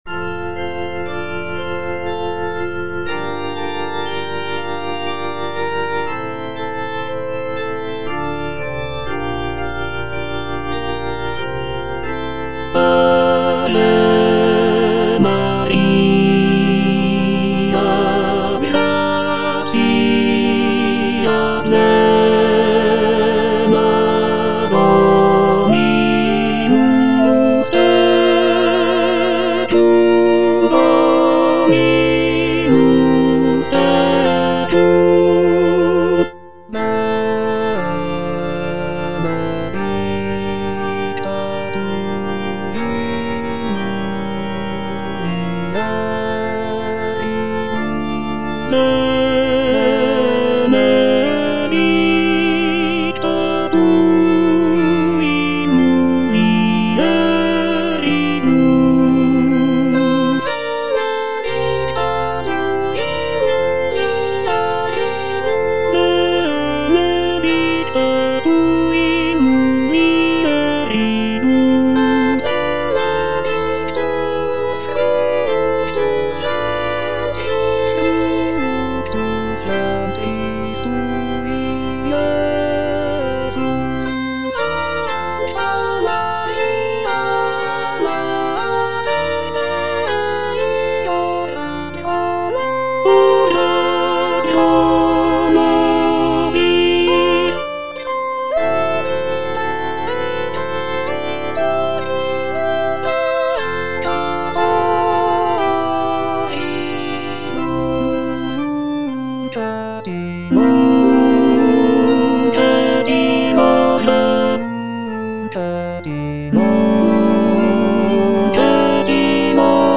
Tenor (Emphasised voice and other voices)
choral piece